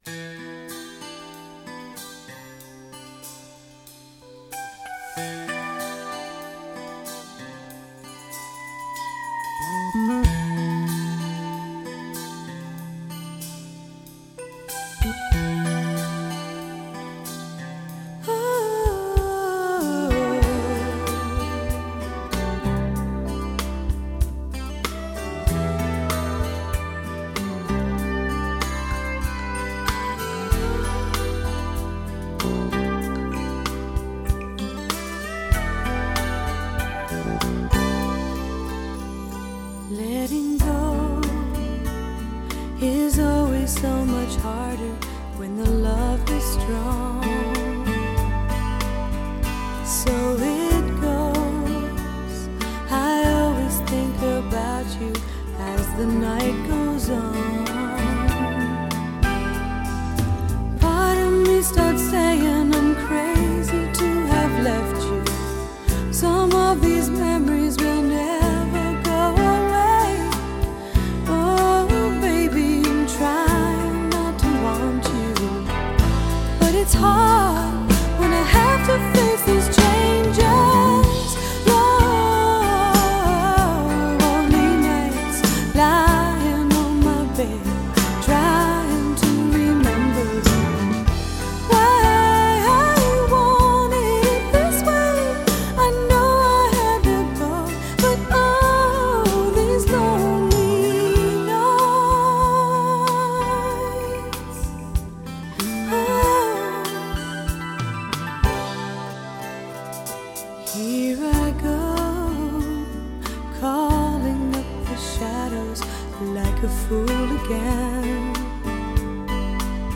★1990 年代人手一張的女聲測試片，以原始母帶精心重製，收錄三首原版未收錄之新曲！